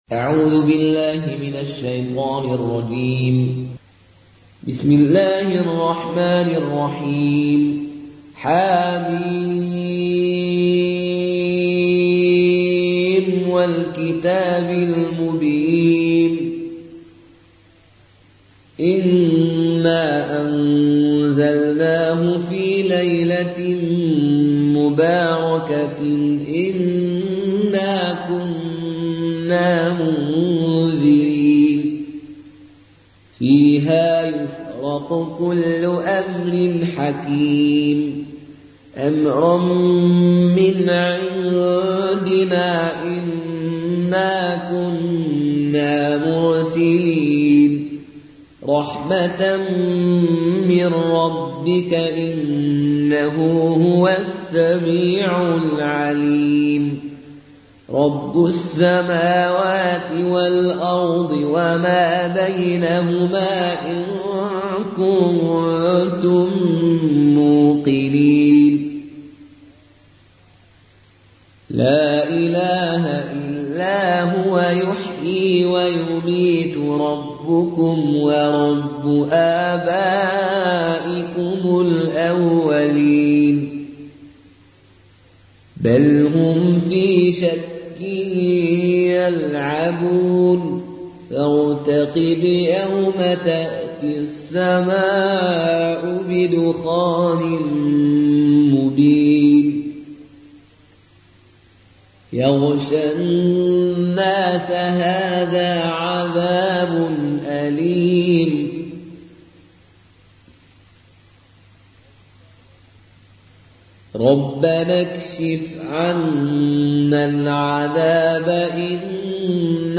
فالون از نافع